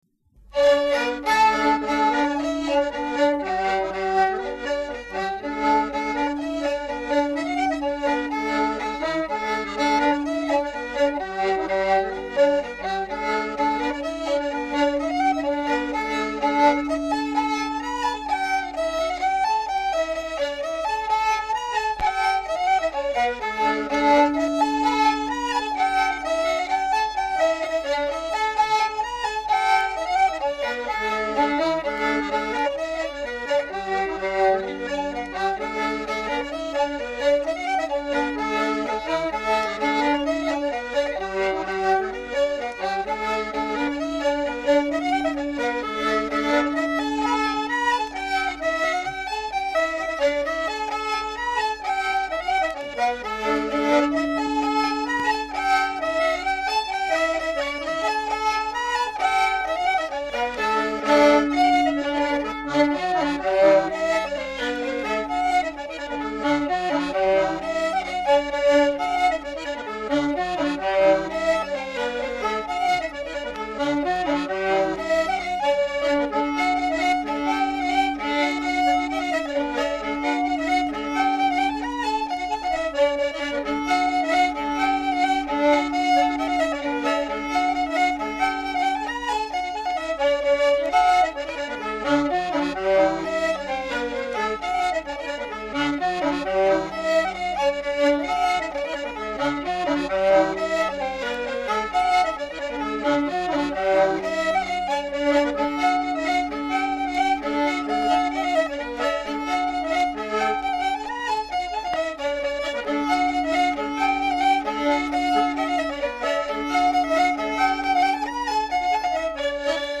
button accordion
fiddle
There are no reels at all, and only one set of double jigs – the rest of the album consists almost exclusively of Kerry slides and polkas played with the strong rhythmic emphasis on the backbeat characteristic of the Sliabh Luachra region. It is very clear from their sparse, unobscured style that these musicians are of that generation whose music was played, at least publicly, for purposes of dancing, rather than for simply the pleasure of listening.
SAMPLE: Some classic polkas to give you an idea